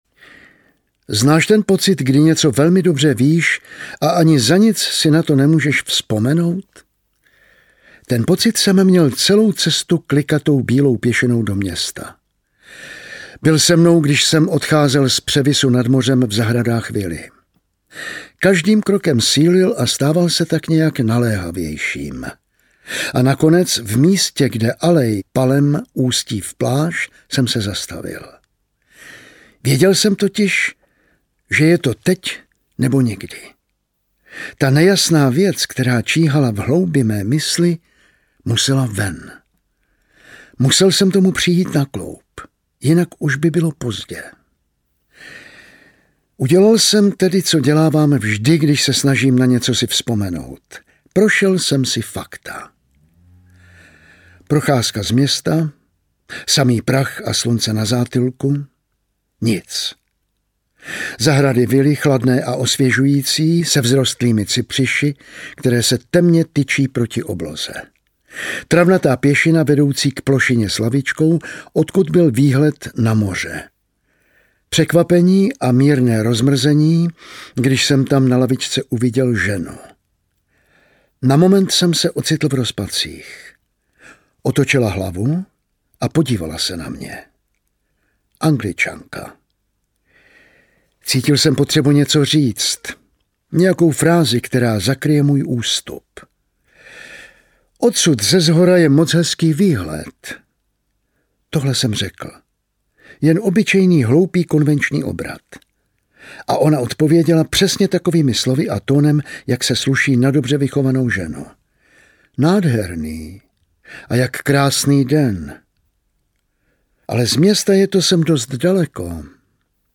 Čte Jan Vlasák